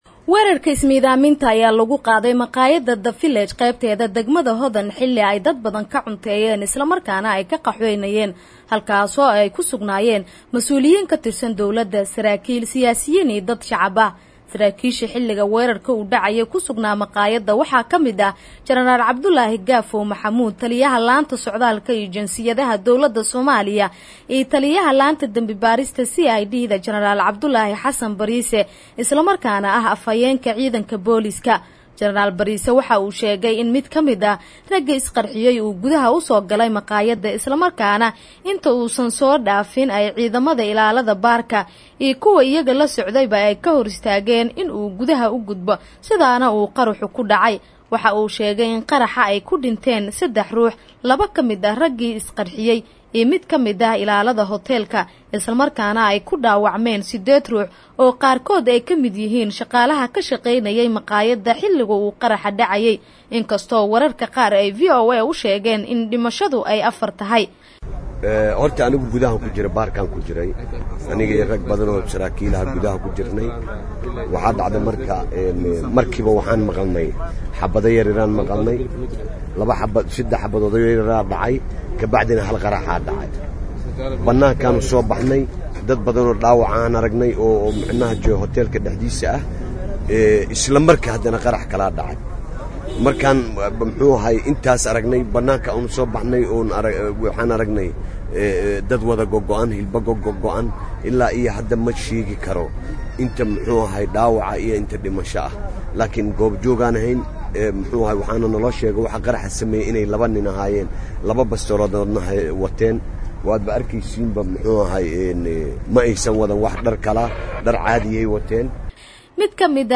Dhageyso warbixinta Qaraxa Makhaayadda Village